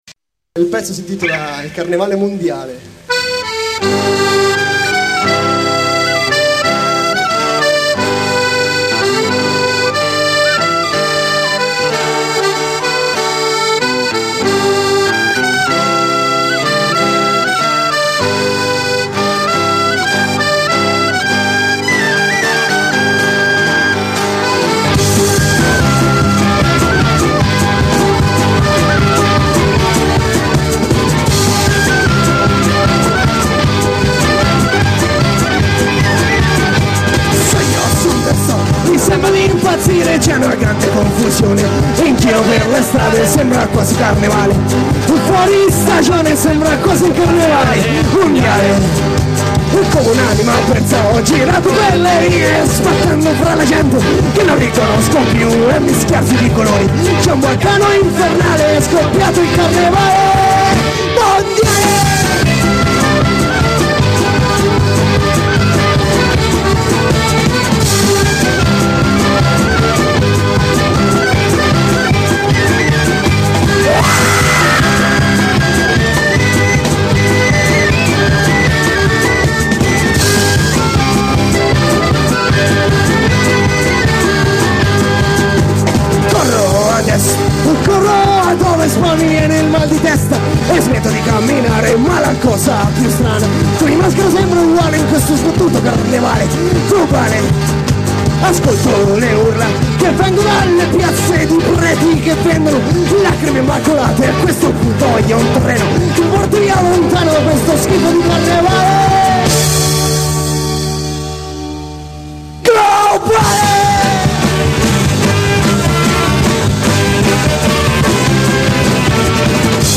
LIVE in Senigallia 1998